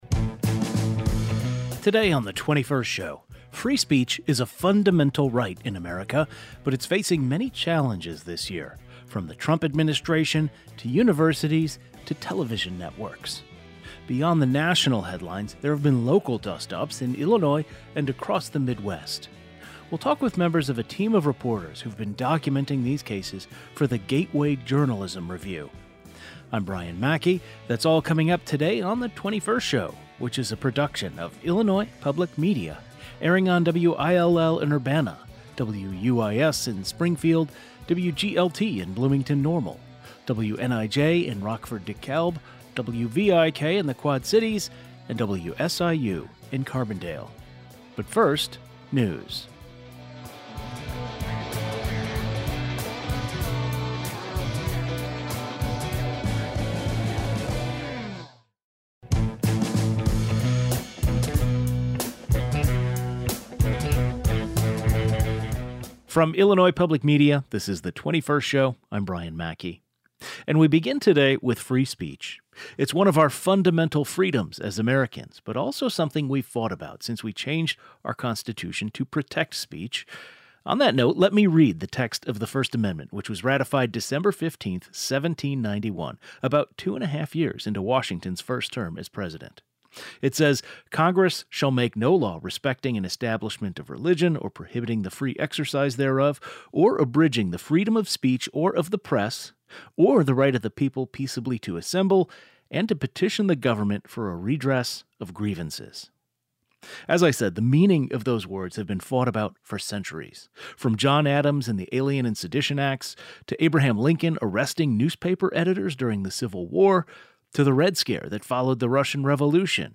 Fee speech is a fundamental right in America, but it’s facing many challenges this year from the Trump administration to universities to television networks. A trio of Illinois journalists who’ve been covering free speech challenges across the Midwest join today's discussion.